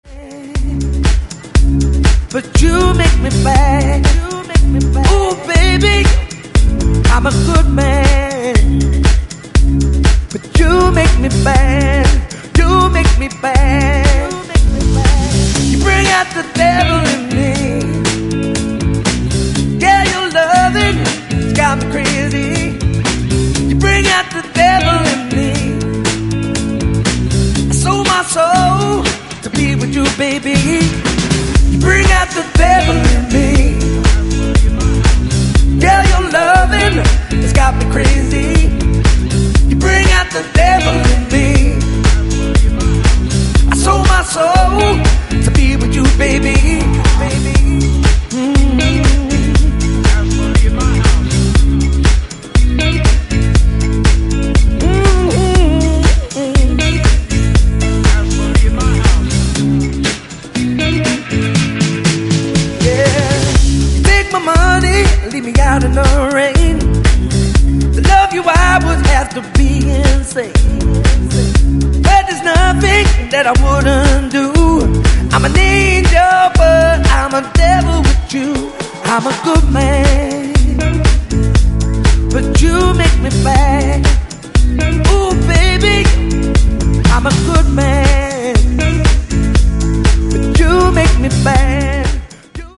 ブギーでソウルフルなディスコ、ファンク、ハウス、を散りばめたグレイト過ぎる好内容です！